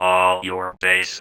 VVE1 Vocoder Phrases 01.wav